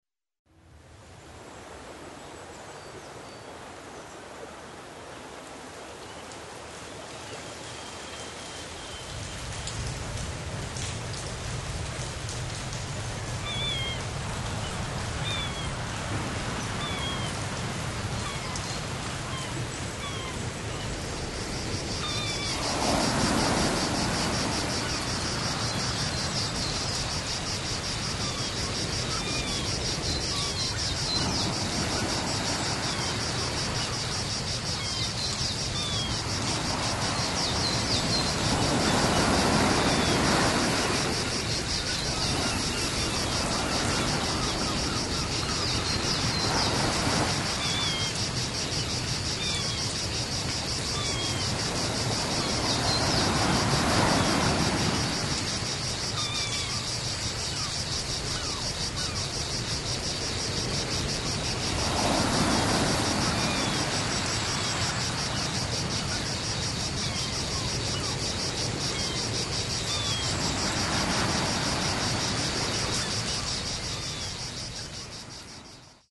Audio Montage
all the sounds from a white noise machine mixed together. have fun sleeping.